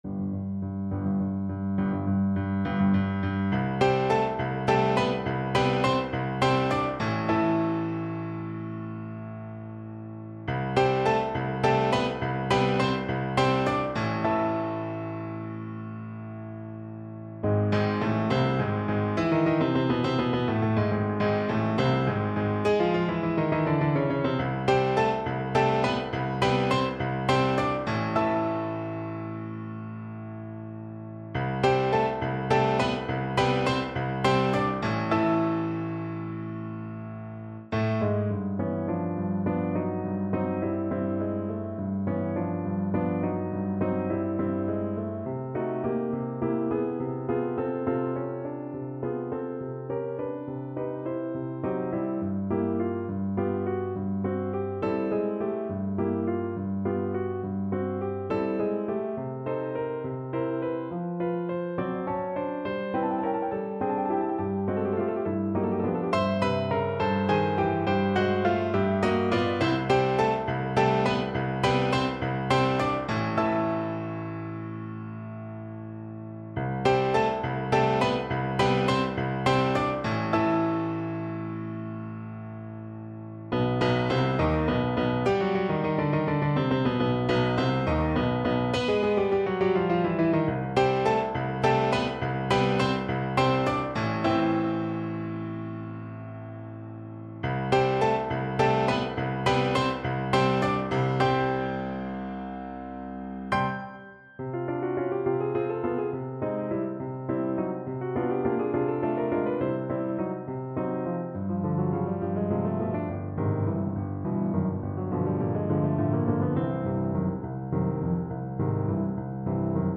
3/4 (View more 3/4 Music)
Allegro .=69 .=69 (View more music marked Allegro)
Classical (View more Classical French Horn Music)
Dramatic & Epic music for French Horn